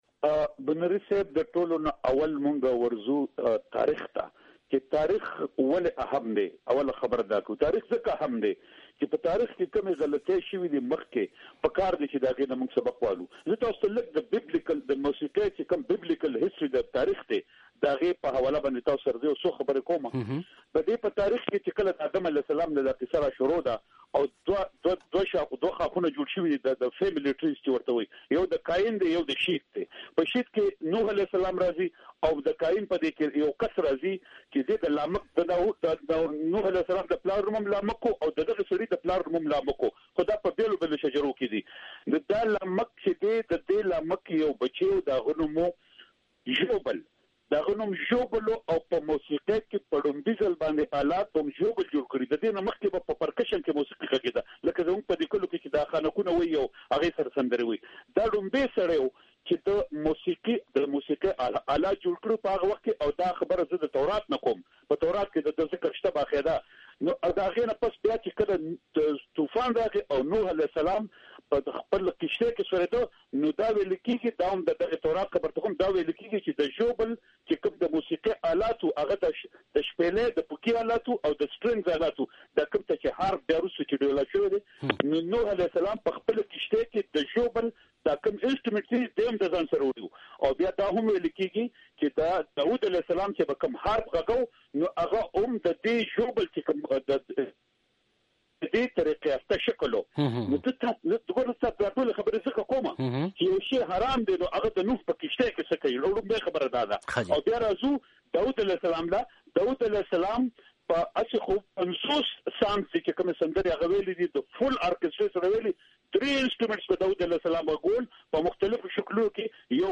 د ښاغلي سردار علي ټکر مرکه